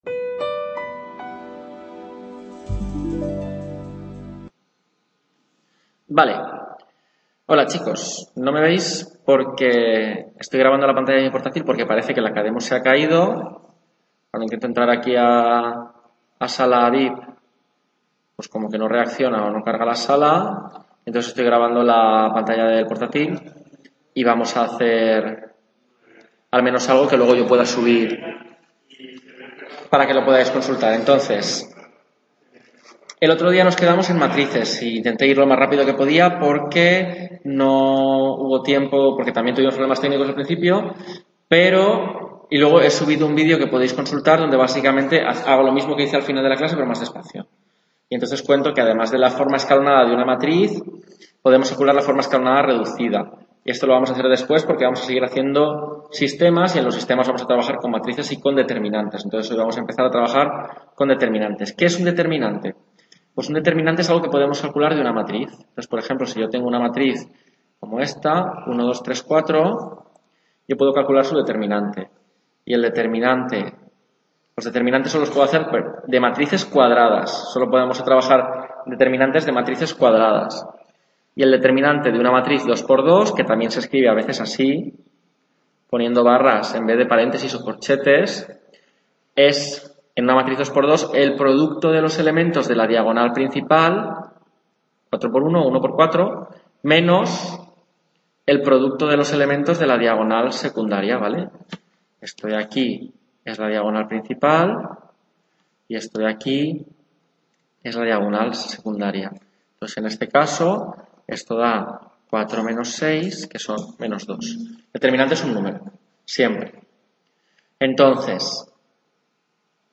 Tutoría 3 Álgebra: Determinantes y Sistemas de… | Repositorio Digital